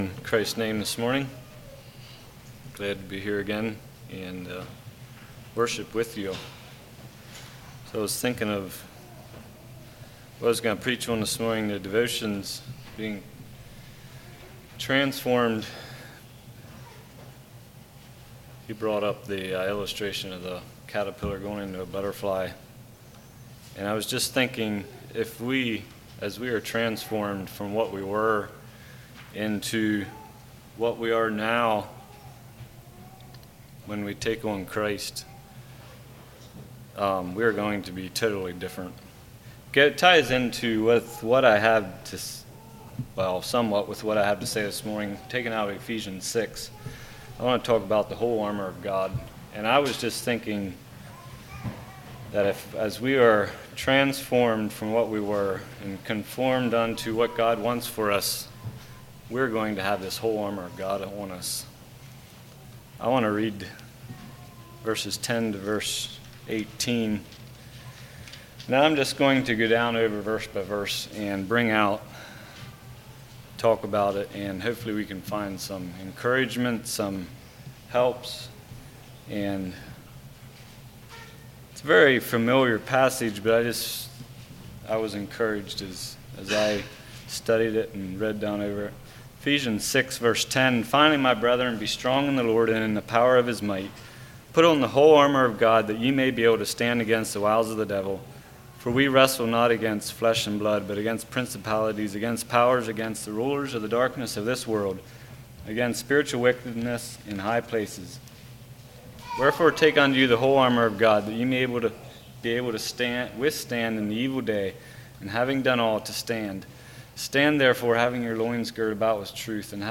Sermons
Kirkwood | All Day Meetings 2024